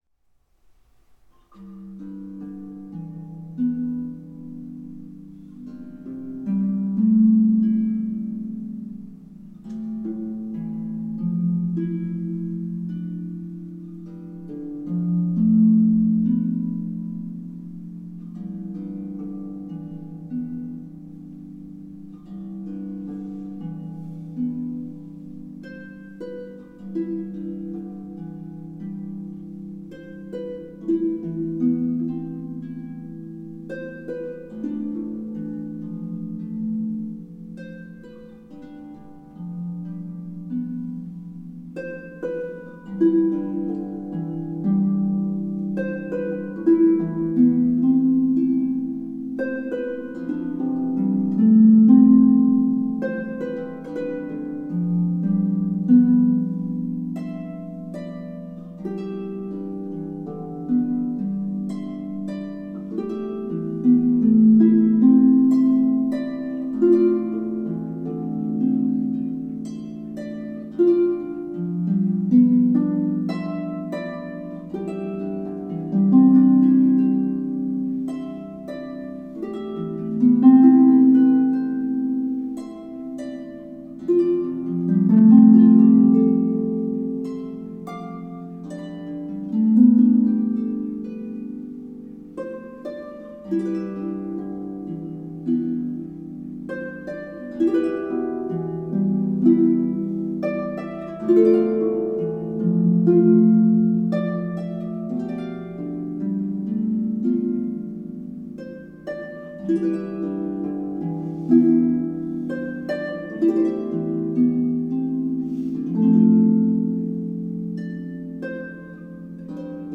for solo lever or pedal harp
This piece is so beautiful and peaceful.